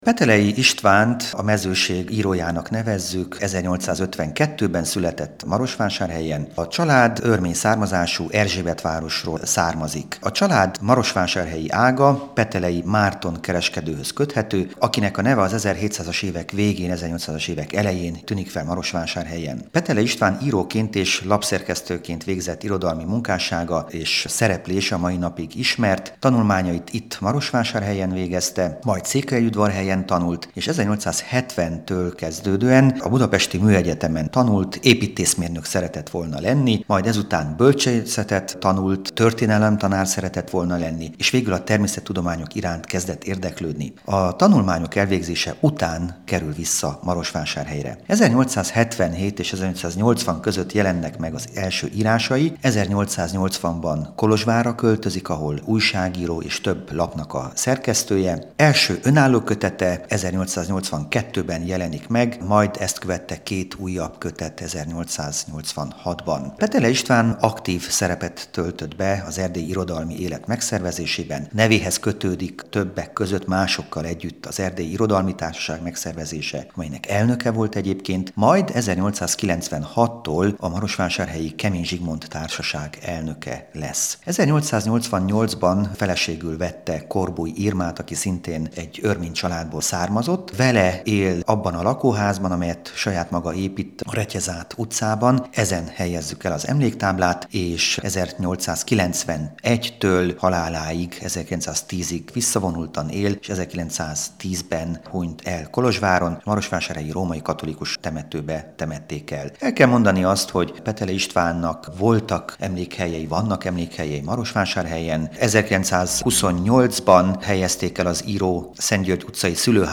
Nem egyszerű Marosvásárhelyen emléktáblát engedélyeztetni, hiszen csak ez a folyamat 9 hónapot ölelt fel, viszont szerencsére mindent sikerült elrendezni, így szombaton várjuk az érdeklődőket, hiszen ez egy olyan megemlékezés és egyben ünnep, ahol mindenki részt vehet, nyomatékosította